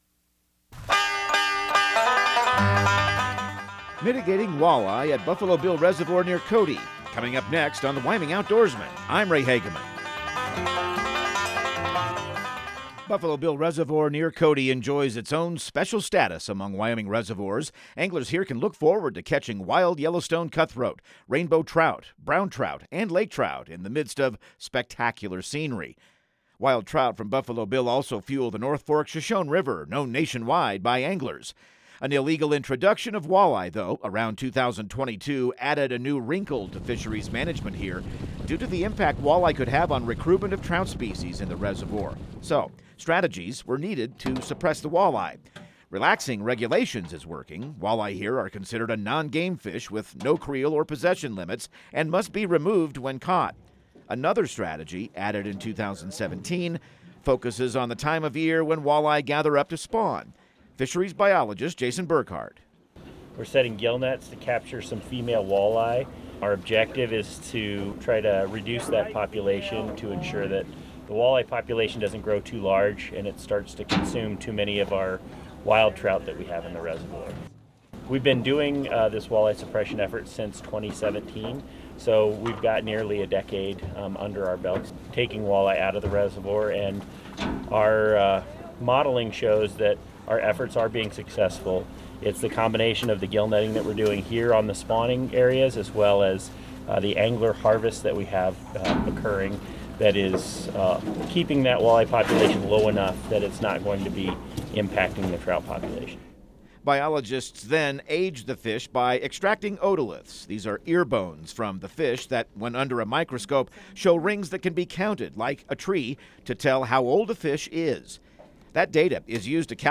Radio news | Week of June 16